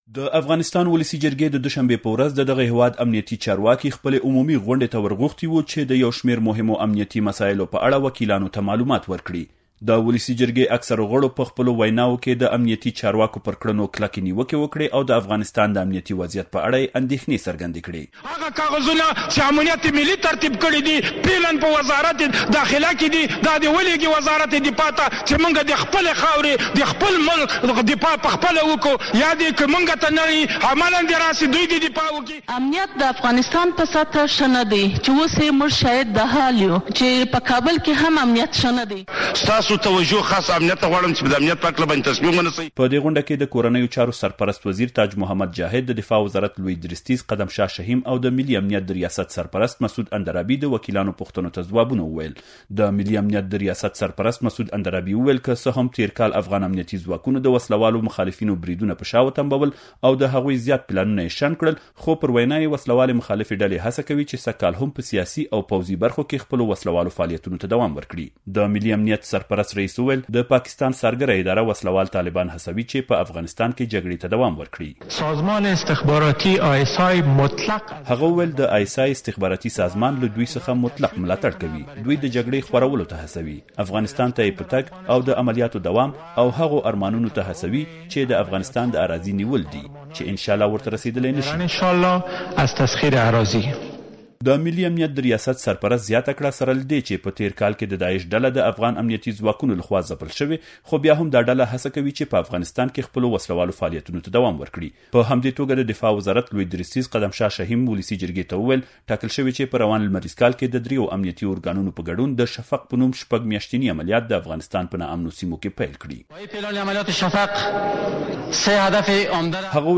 مرکې
د خوست د ولایتي شورا د غړې زهره جلال سره د امریکا غږ آشنا رادیو مرکه: